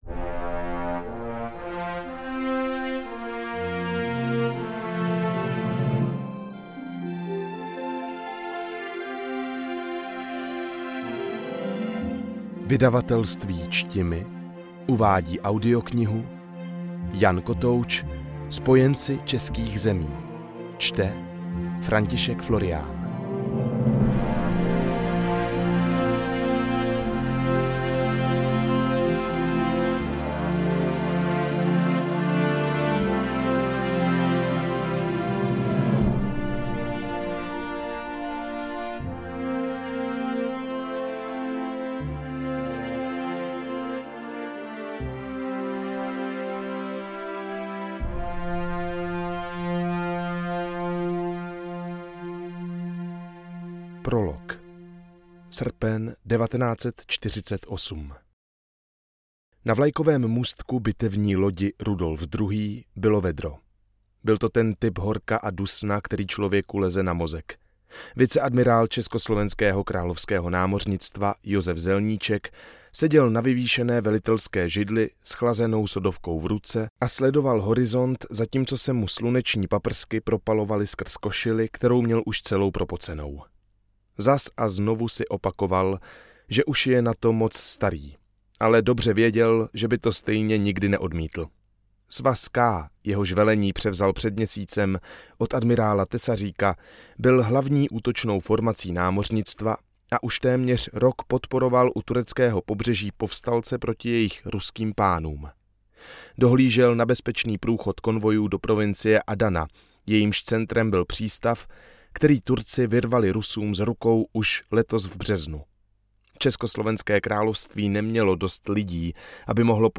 Hudba: Ondřej Morcinek